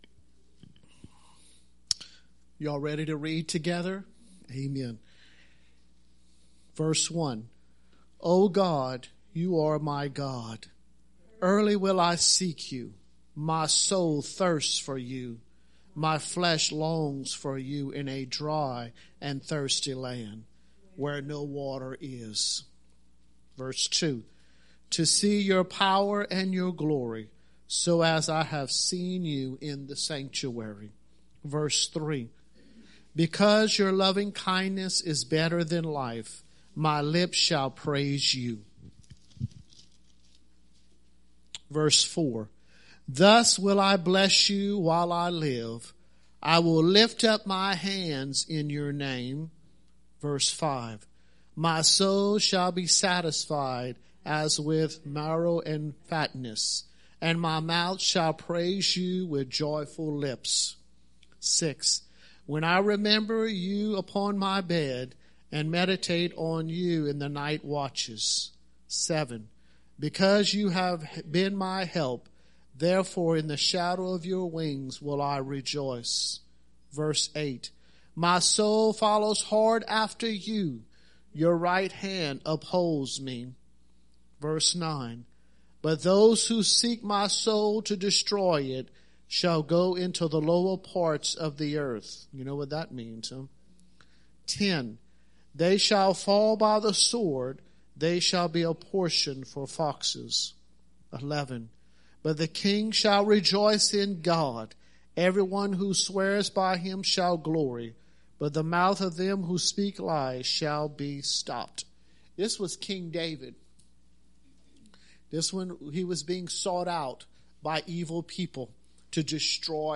Gueydan International Church's Church Sermons.